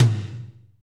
TOM S S M0YL.wav